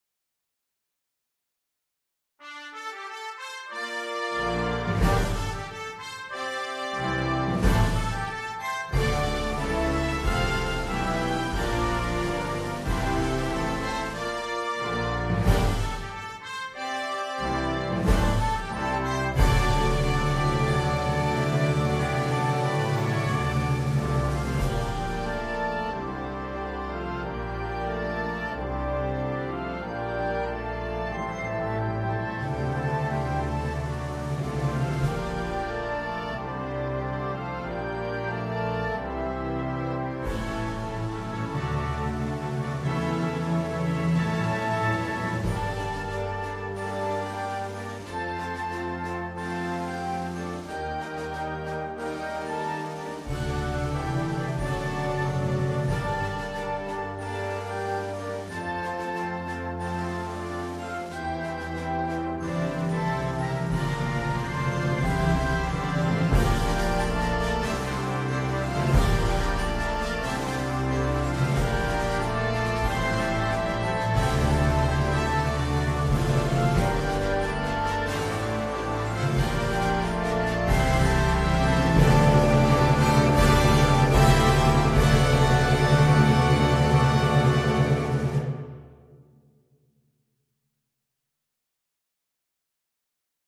交响管乐/军乐